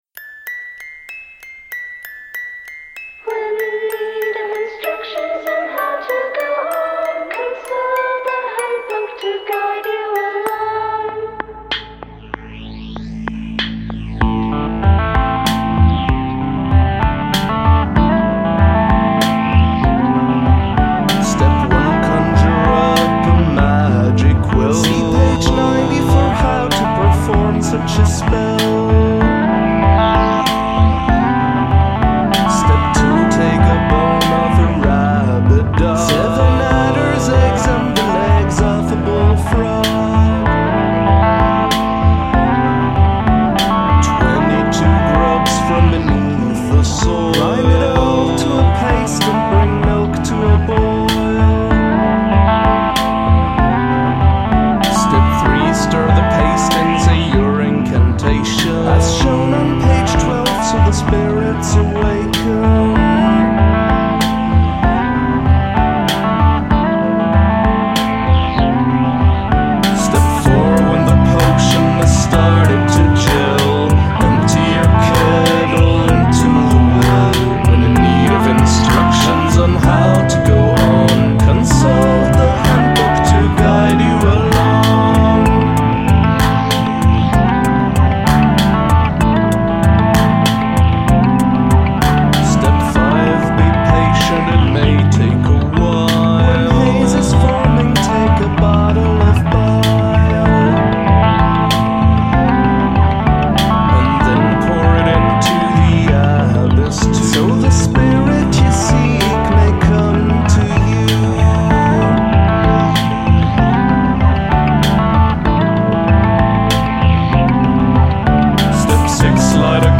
This is so creepy!